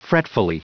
Prononciation du mot fretfully en anglais (fichier audio)